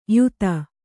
♪ yuta